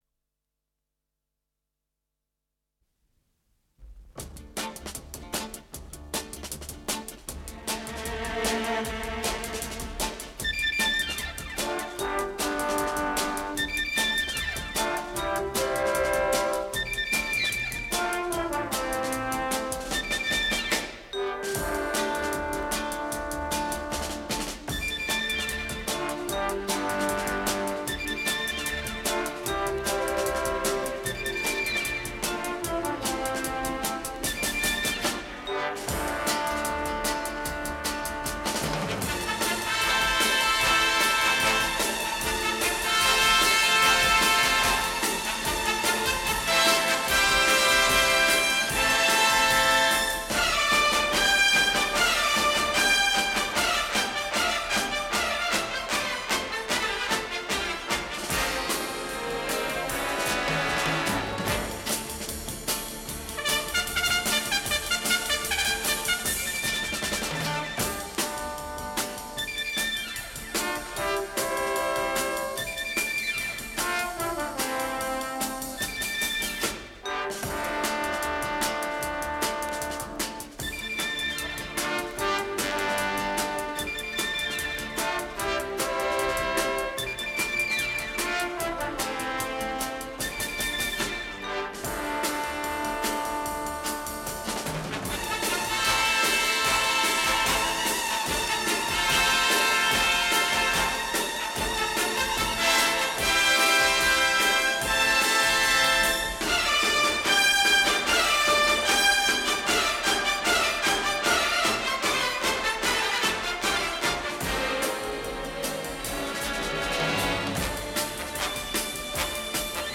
Стерео